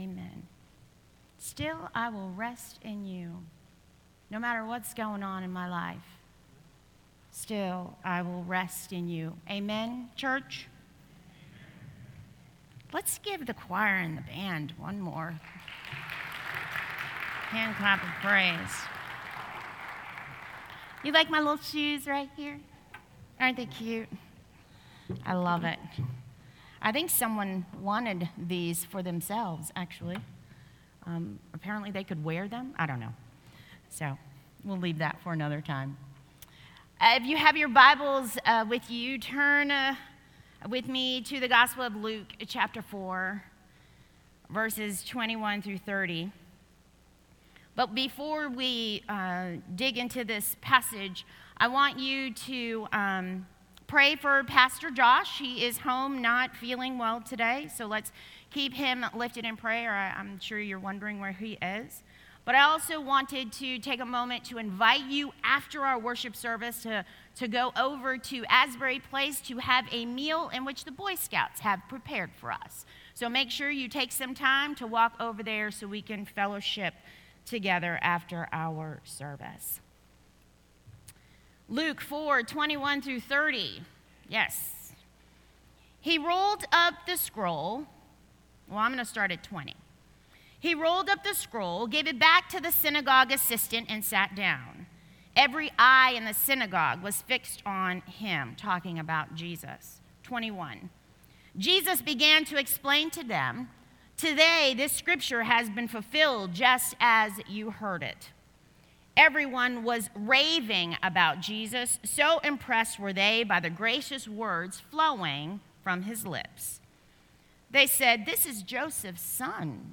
Sermon Archives - Cokesbury Church